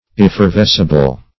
Effervescible \Ef`fer*ves"ci*ble\, a. Capable of effervescing.